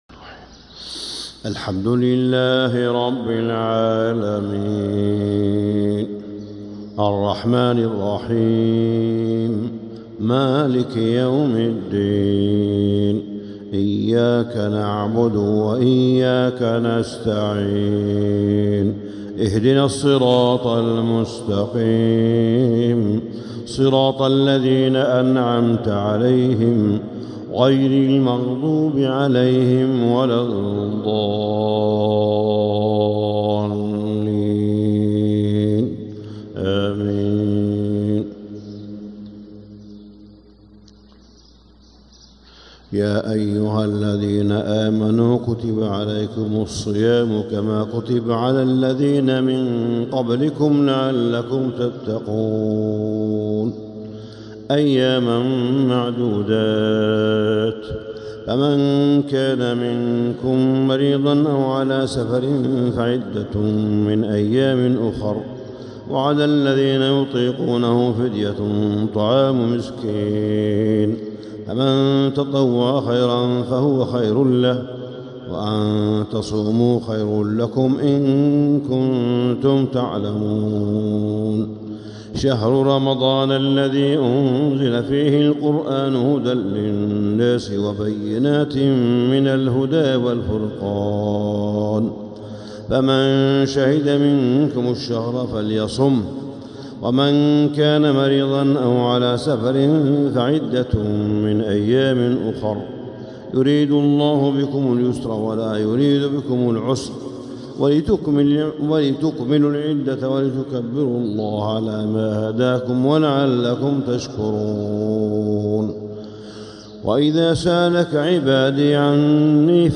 النور التام لفروض المسجد الحرام 🕋 من 1 رمضان إلى 7 رمضان 1446هـ ( الحلقة 47 ) > إصدارات النور التام لفروض المسجد الحرام 🕋 > الإصدارات الشهرية لتلاوات الحرم المكي 🕋 ( مميز ) > المزيد - تلاوات الحرمين